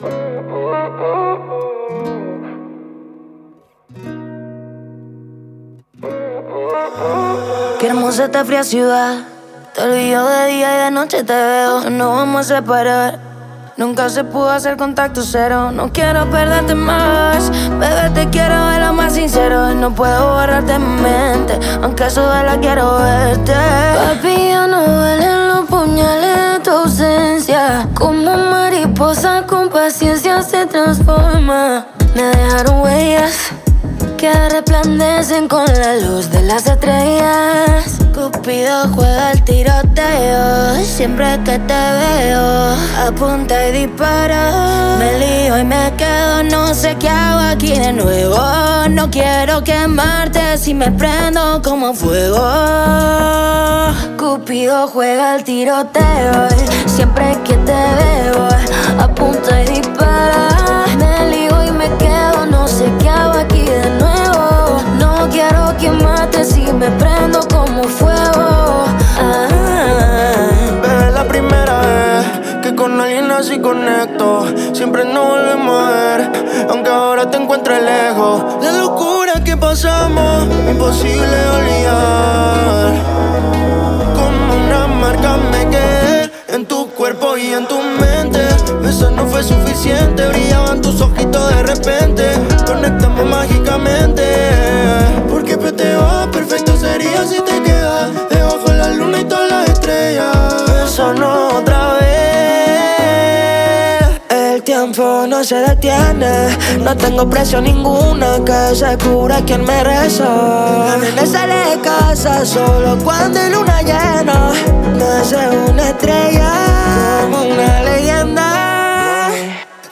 género urbano
voces